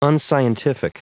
Prononciation du mot unscientific en anglais (fichier audio)
Prononciation du mot : unscientific